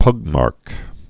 (pŭgmärk)